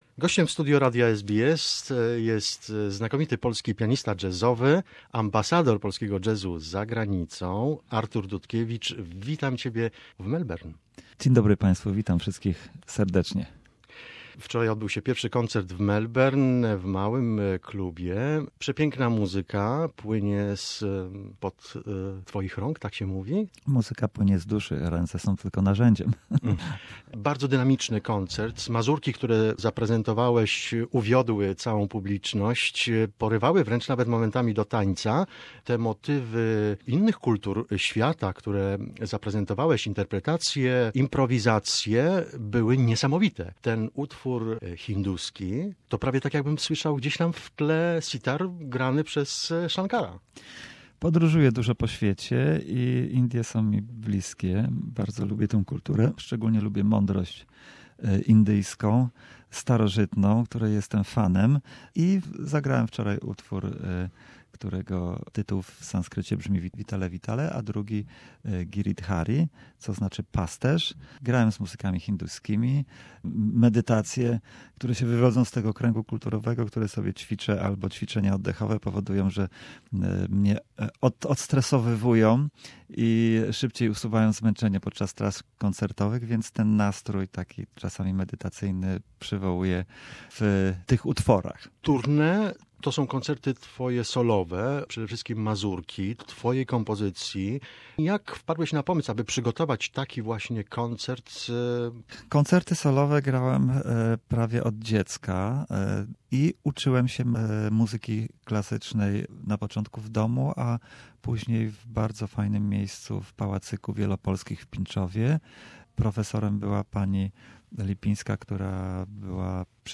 contemporary jazz mazurkas
is a Polish folk dance in triple meter.